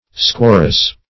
squarrous - definition of squarrous - synonyms, pronunciation, spelling from Free Dictionary Search Result for " squarrous" : The Collaborative International Dictionary of English v.0.48: Squarrous \Squar"rous\ (skw[a^]r"r[u^]s or skw[o^]r"-), a. Squarrose.